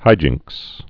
(hījĭngks)